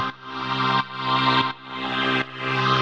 Index of /musicradar/sidechained-samples/170bpm
GnS_Pad-alesis1:2_170-C.wav